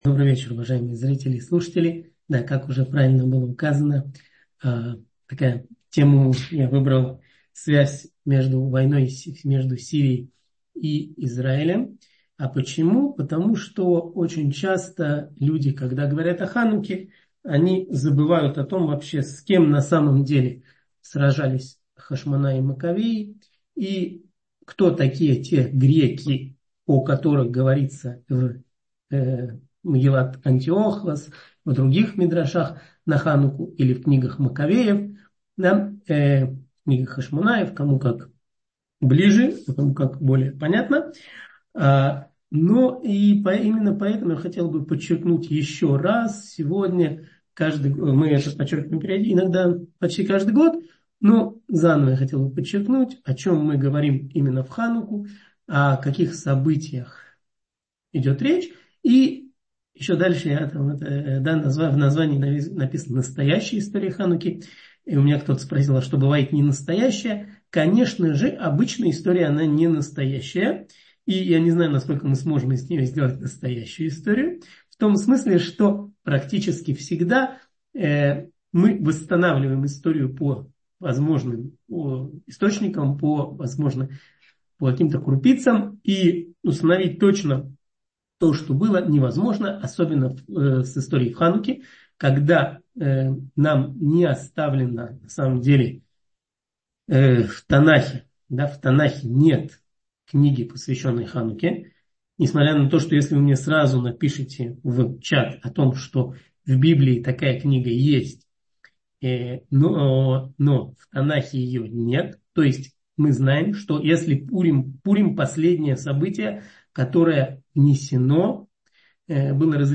Настоящая история Хануки — слушать лекции раввинов онлайн | Еврейские аудиоуроки по теме «Еврейские праздники» на Толдот.ру